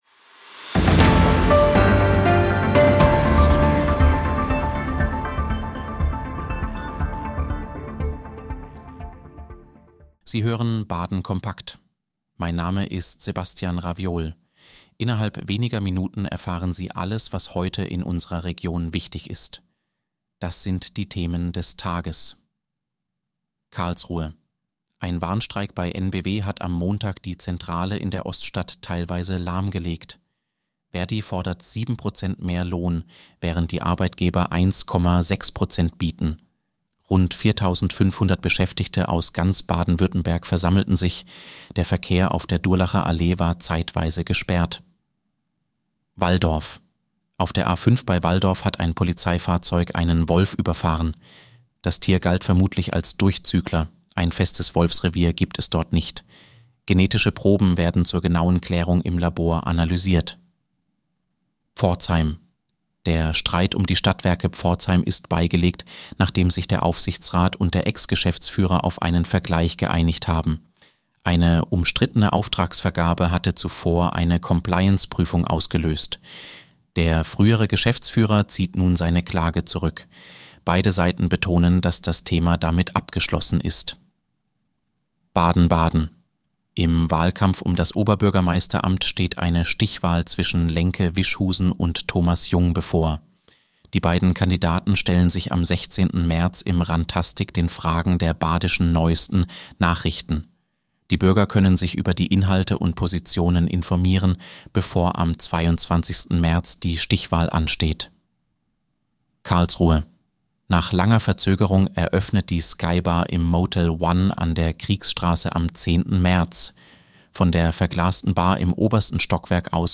Nachrichtenüberblick: Warnstreik bei EnBW